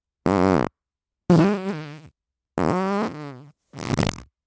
FART SOUND 44